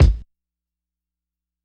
Havoc Kick 13.wav